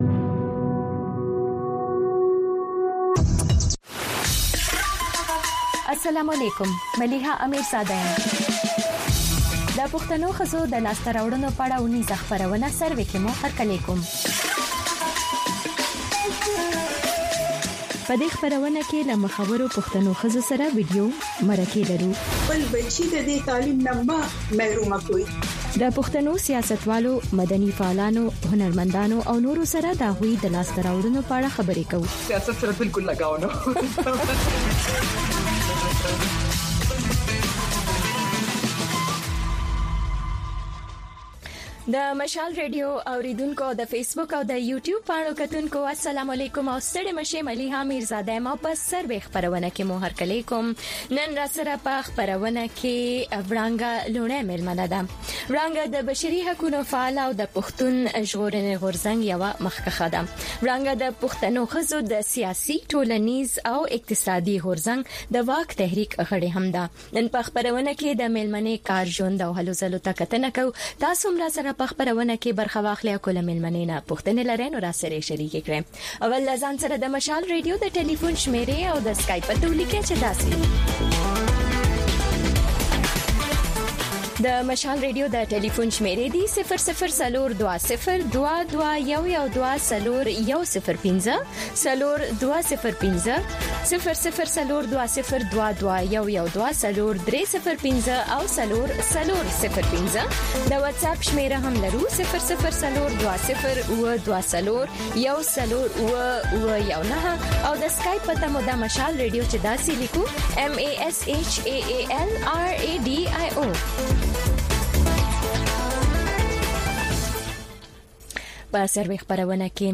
د وی او اې ډيوه راډيو سهرنې خبرونه چالان کړئ اؤ د ورځې دمهمو تازه خبرونو سرليکونه واورئ. په دغه خبرونو کې د نړيوالو، سيمه ايزو اؤمقامى خبرونو هغه مهم اړخونه چې سيمې اؤ پښتنې ټولنې پورې اړه لري شامل دي. دخبرونو په دې جامع وخت کې دسياسياتو، اقتصاد، هنر ، ټنګ ټکور، روغتيا، موسم اؤ لوبو په حقله ځانګړې ورځنۍ فيچرې شاملې دي.